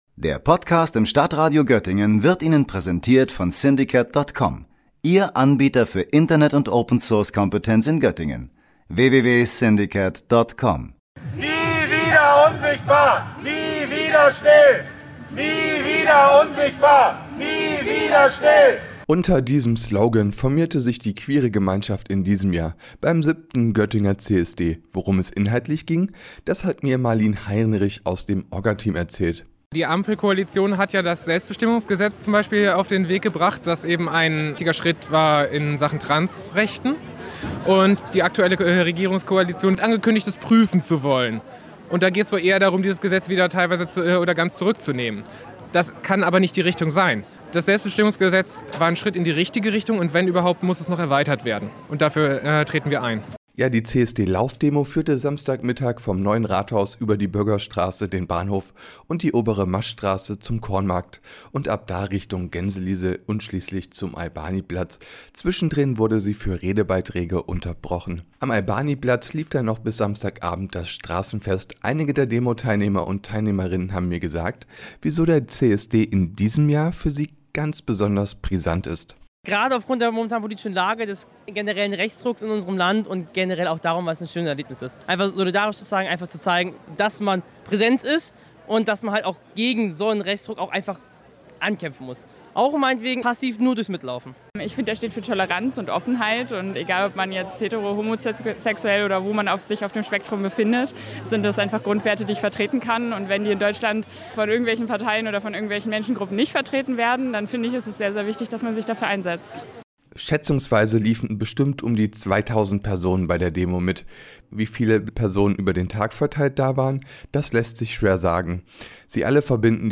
Sichtbar war das beim Demozug.